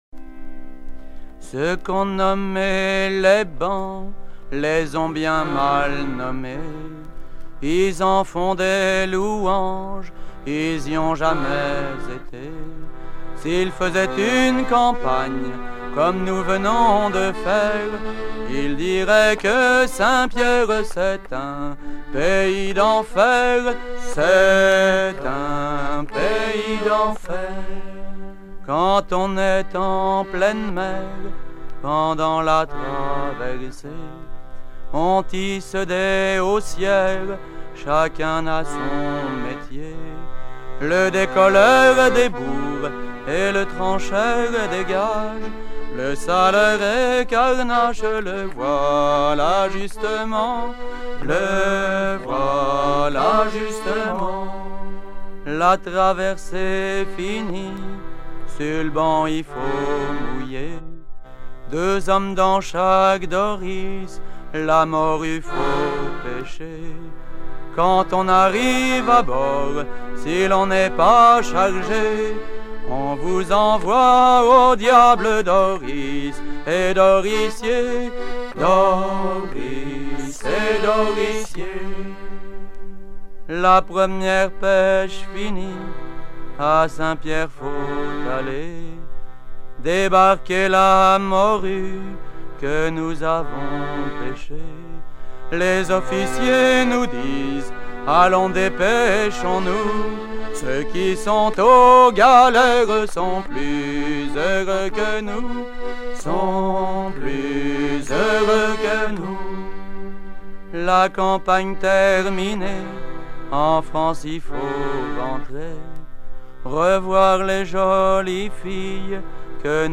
Version recueillie en 1976
Chants de marins traditionnels